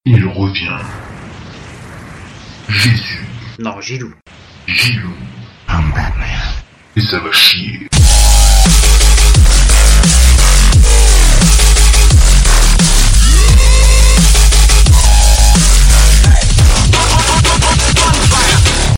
Jingle 1